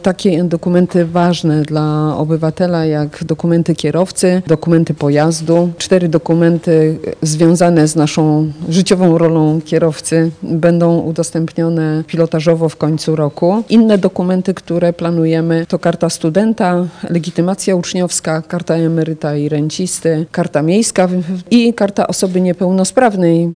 Program testowania usługi mobilnego dowodu osobistego zainaugurowała dziś, podczas swojej wizyty w Ełku minister cyfryzacji Anna Streżyńska.
-W przyszłości planujemy rozszerzenie usługi na inne dokumenty – mówi minister Anna Streżyńska.